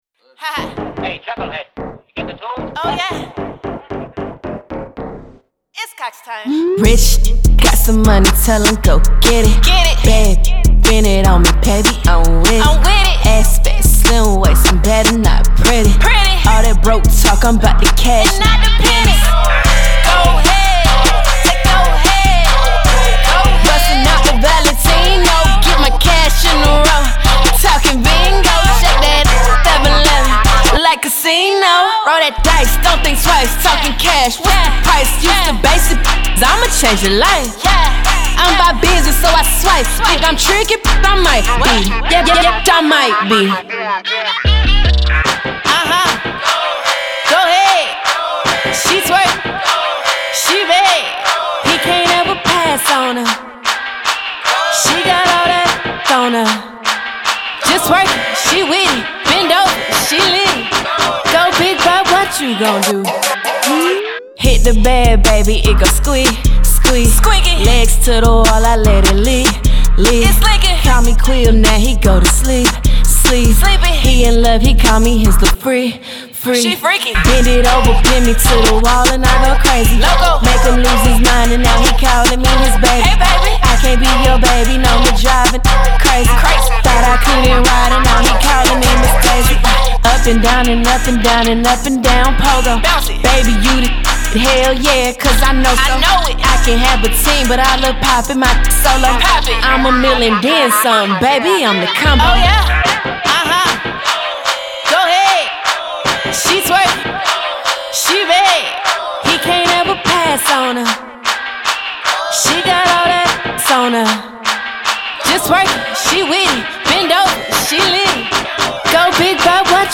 Rap
is a dynamic rap single